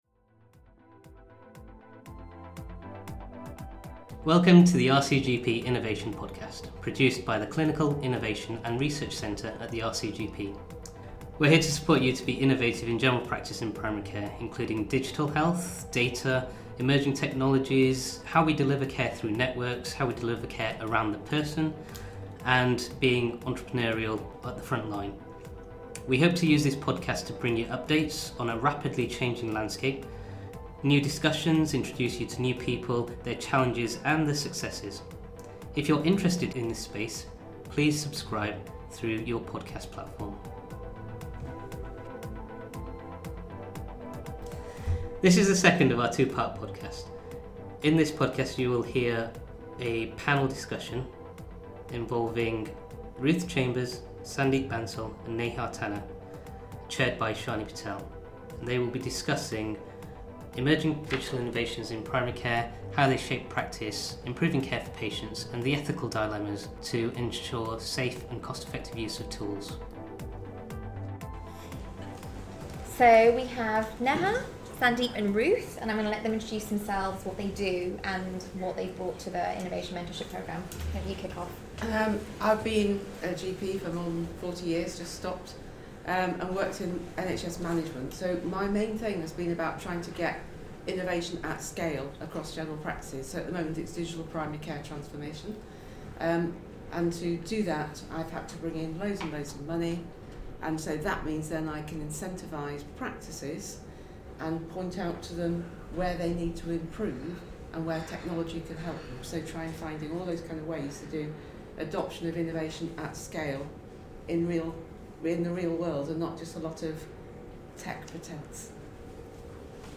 This is part two of a fireside chat with the RCGP’s Innovators Mentorship Programme (IMP) mentors.
This session was recorded at the RCGP’s City Health conference in June 2019, in the ‘ Designing digital solutions for the future: the possibilities ’ workshop.